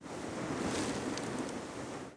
1 channel
A_sand2.mp3